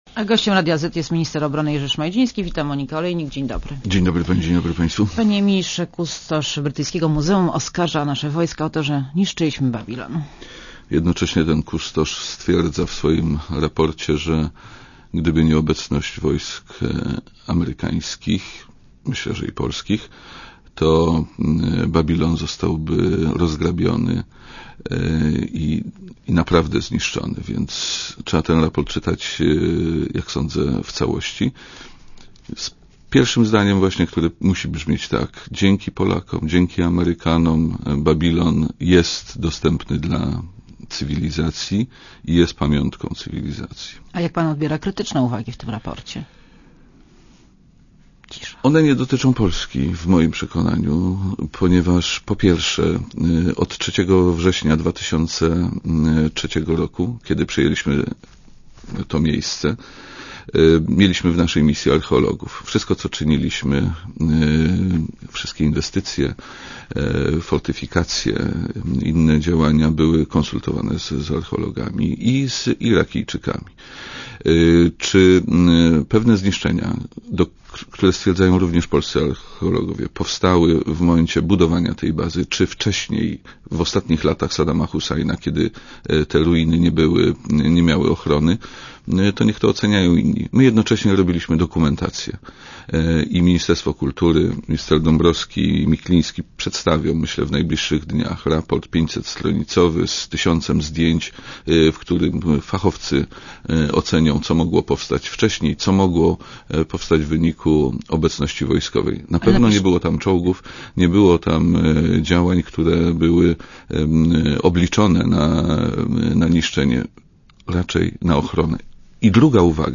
Monika Olejnik rozmawia z Jerzym Szmajdzińskim, ministrem obrony narodowej